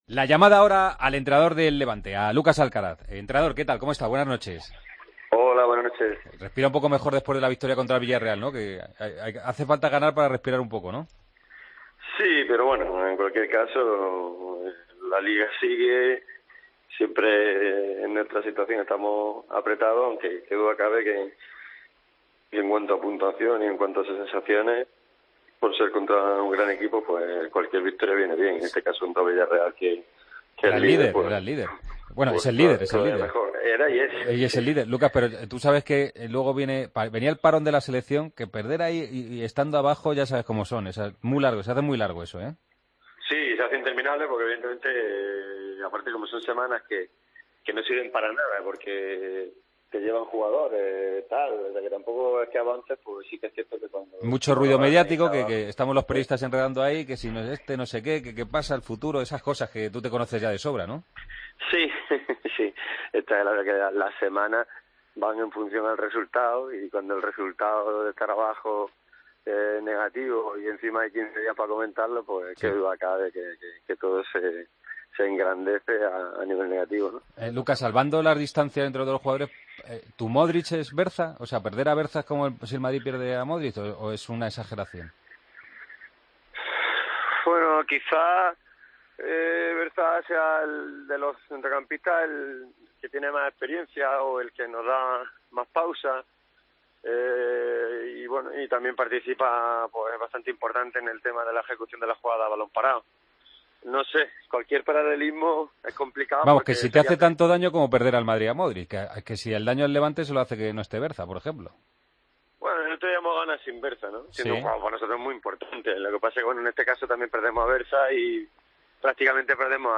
El técnico del Levante analizó el duelo del Santiago Bernabéu ante el Real Madrid.